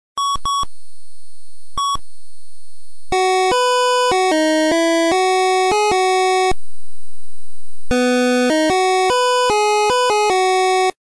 Ton zum Spiel